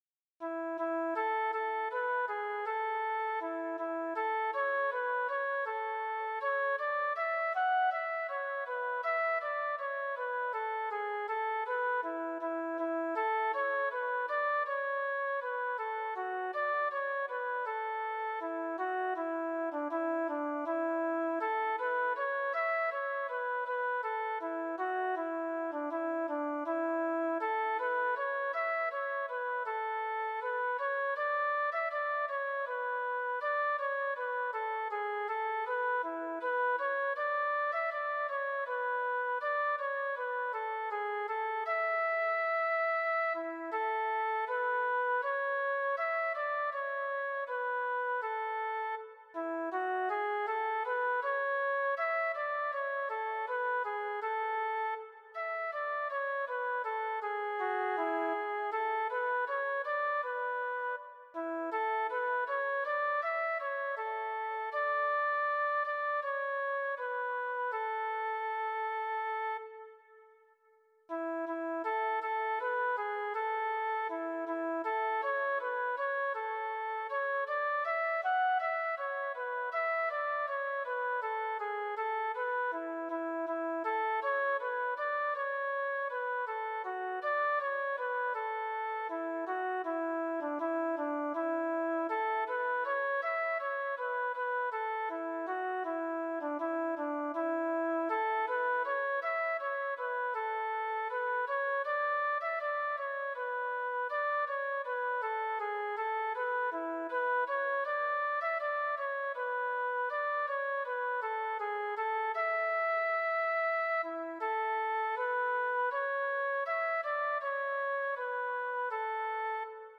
“Una melodía con temperamento.„